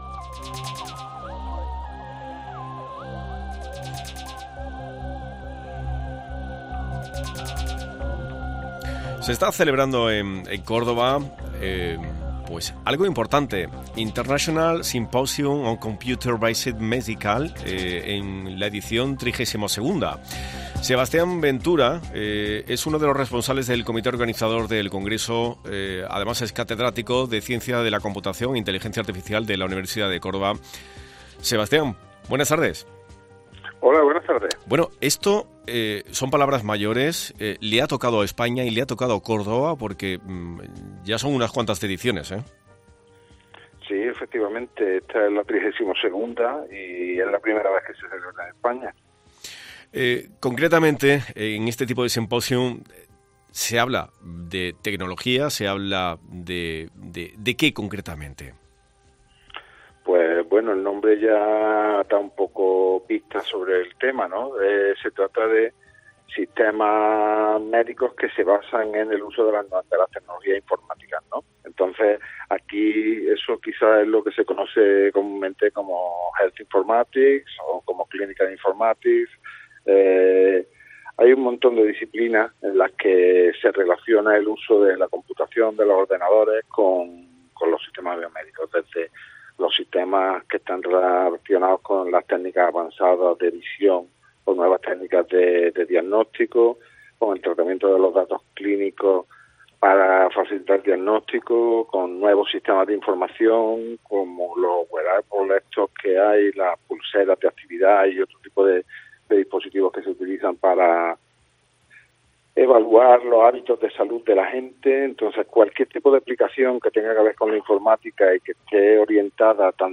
responsables del Comité Organizador